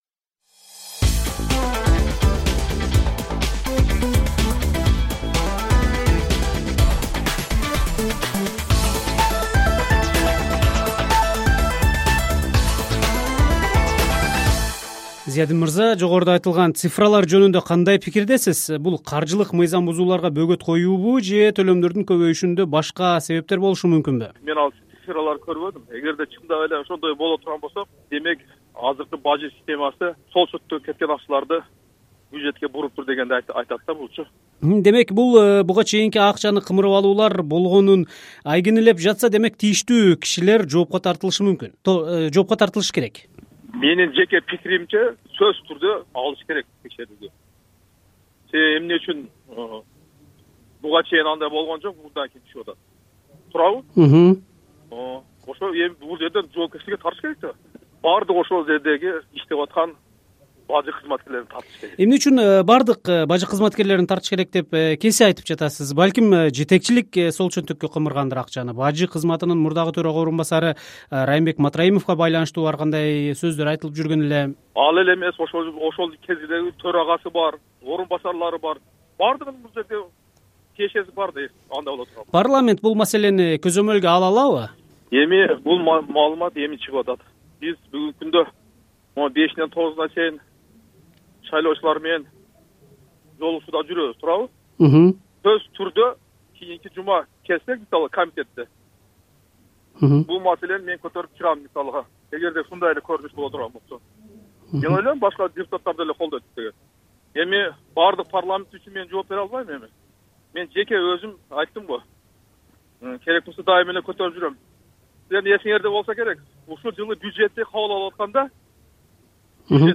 Жогорку Кеңештин "Өнүгүү-Прогресс" фракциясынын депутаты, Бюджет жана финансы комитетинин төрагасынын орун басары Зиядин Жамалдинов бажы тармагында иштеген кызматкерлерди жоопко тартууну сунуш кылды. Мындай пикирин ал "Азаттык" радиосу менен болгон маегинде айтты.